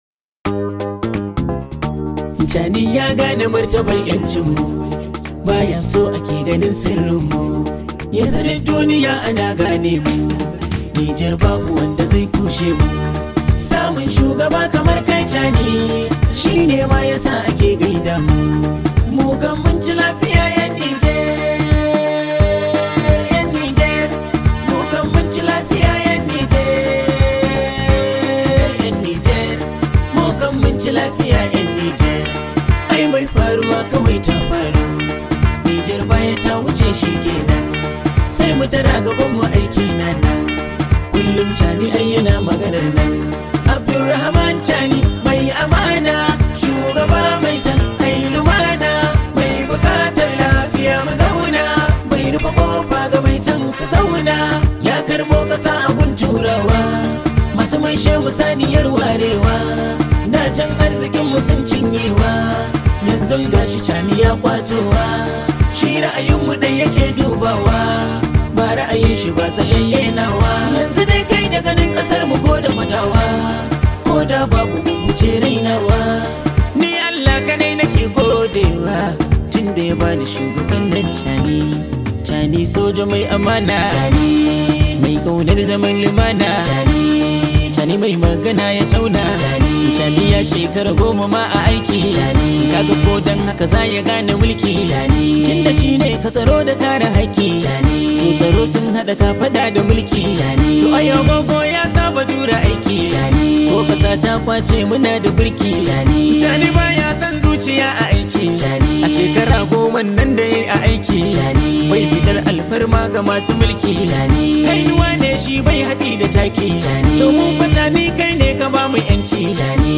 much appreciated hausa song known as
high vibe hausa song